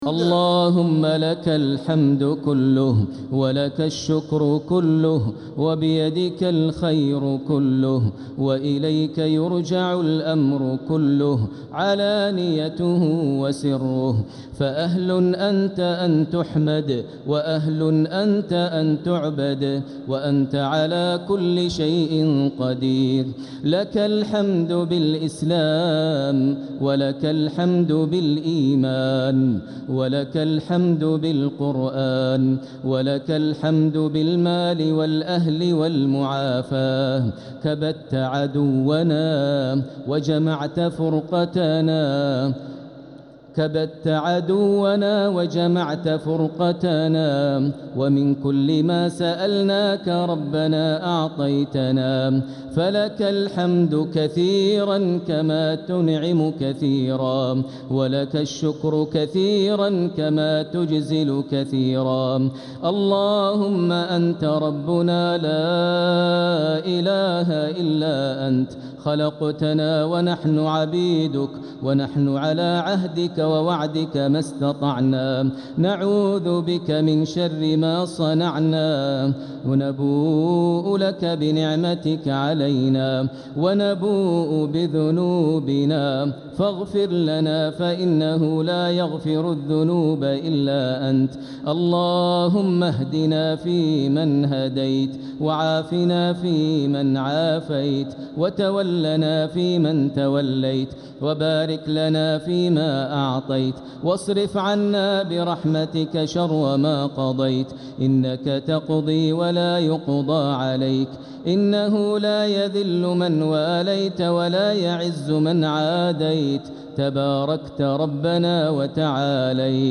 دعاء القنوت ليلة 14 رمضان 1446هـ | Dua for the night of 14 Ramadan 1446H > تراويح الحرم المكي عام 1446 🕋 > التراويح - تلاوات الحرمين